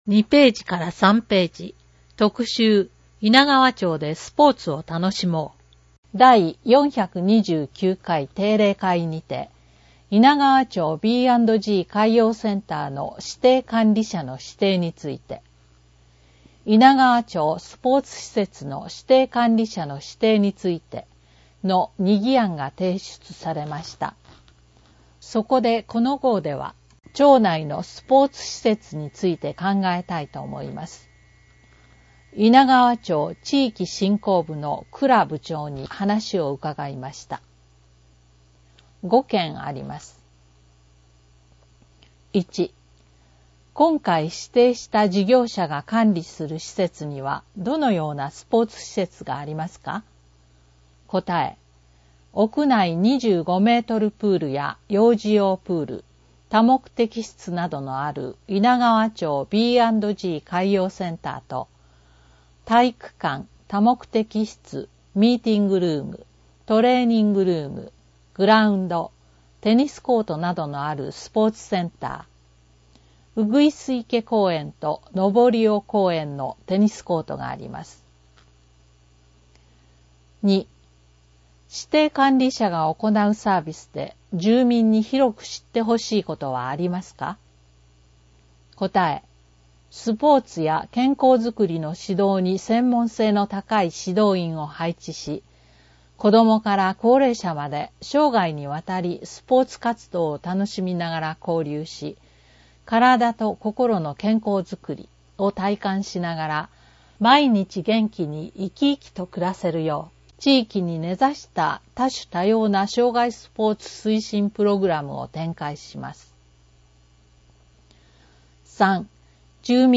制作は猪名川町社会福祉協議会 音訳ボランティア リヴィエールの方々の協力によるものです。